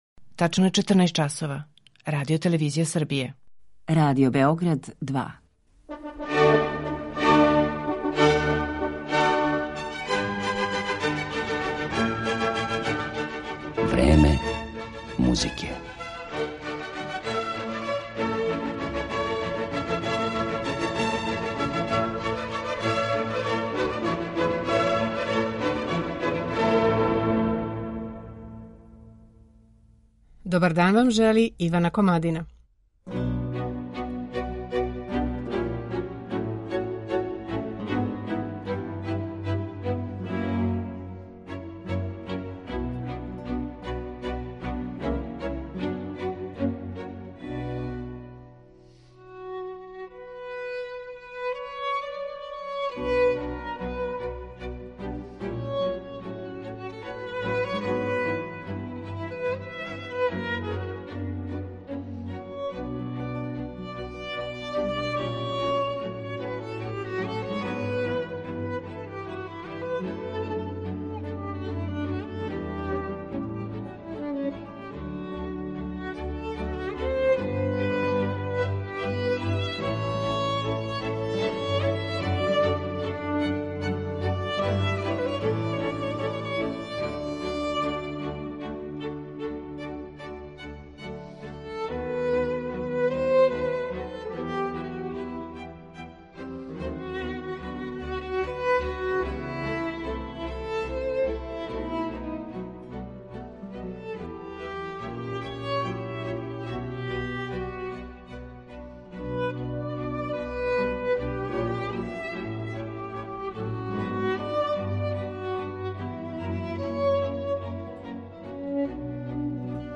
Никола Бенедети, виолина